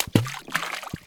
splash-small.wav